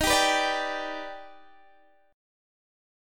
D#+M7 chord